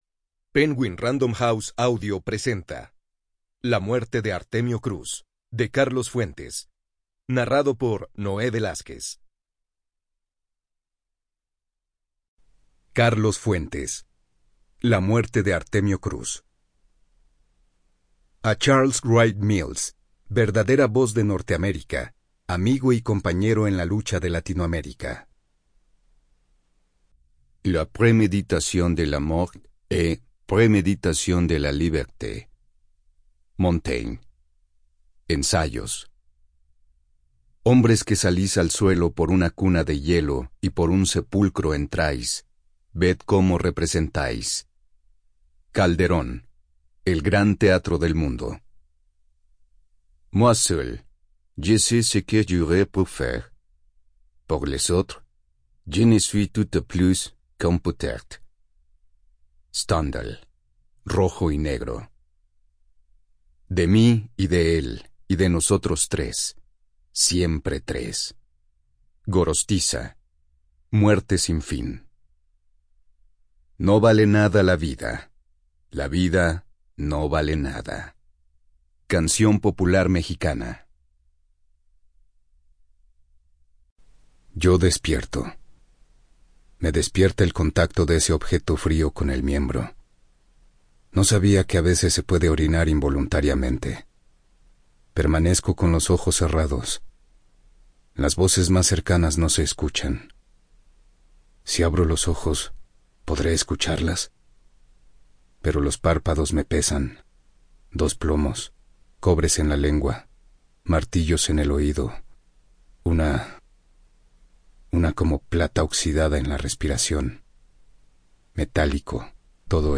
Free Audiobook: When Reading Becomes a Spectator Sport Podcast - muerte de Artemio Cruz - Carlos Fuentes | Free Listening on Podbean App